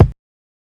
Havoc Kick 29.wav